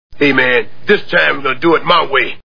The A-Team TV Show Sound Bites